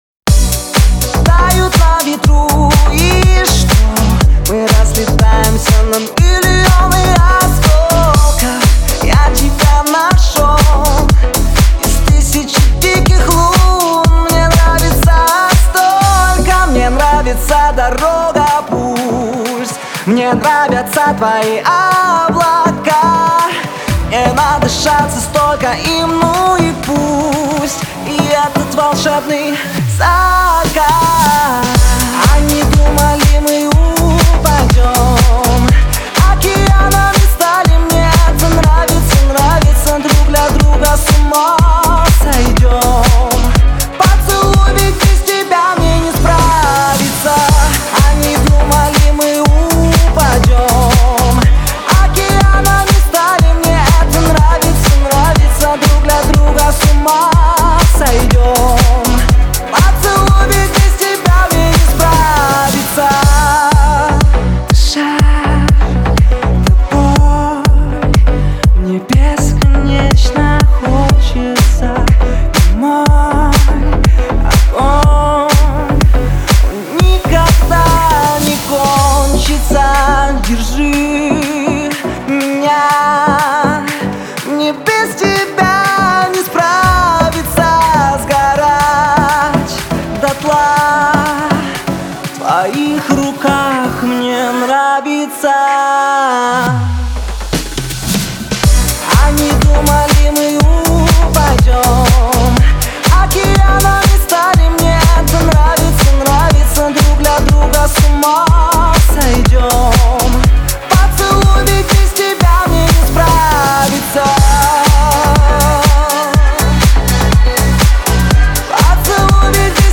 поп-баллада